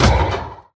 Sound / Minecraft / mob / irongolem / hit3.ogg
hit3.ogg